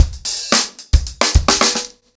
TupidCow-110BPM.11.wav